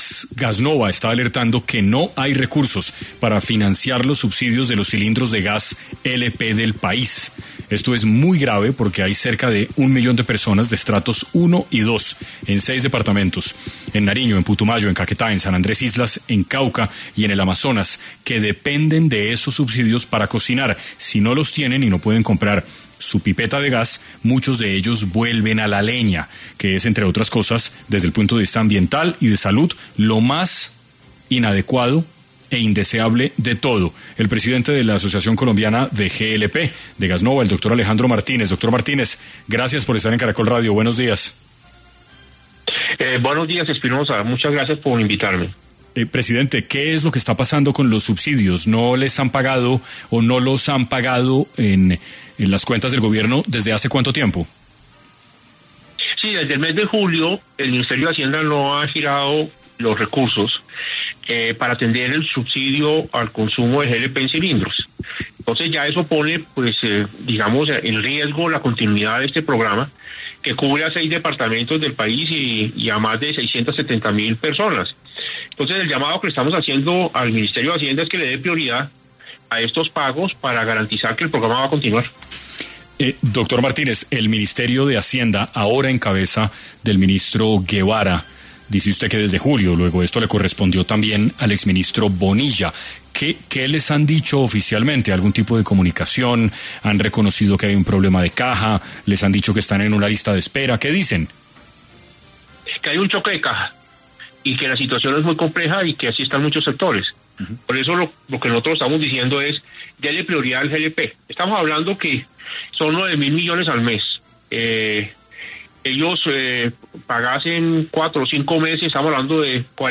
En Caracol Radio